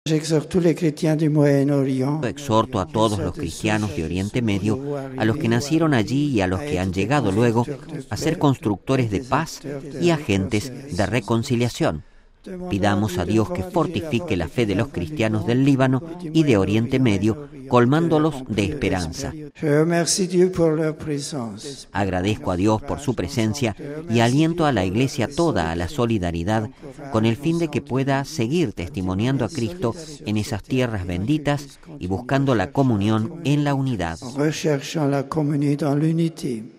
En sus palabras, después de la catequesis, el Papa exhortó ayer a todos los cristianos de Líbano y Oriente Medio a ser “constructores de paz y agentes de reconciliación”.